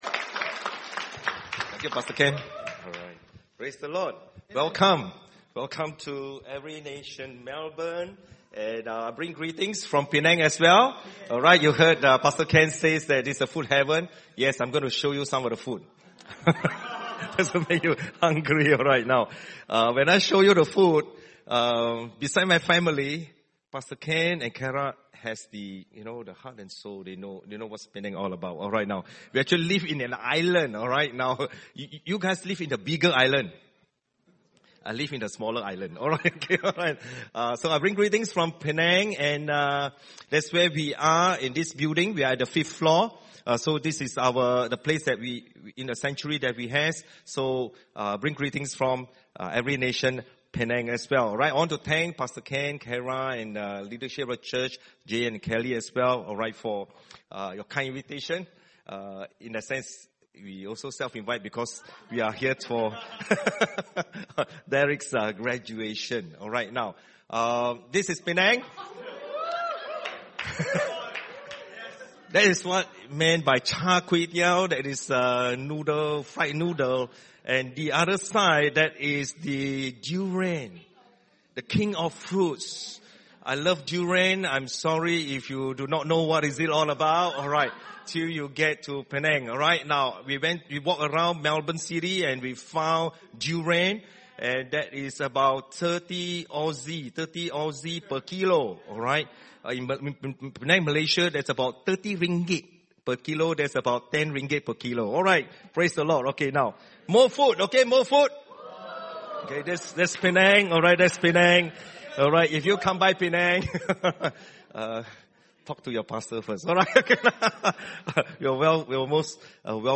by enmelbourne | Nov 8, 2019 | ENM Sermon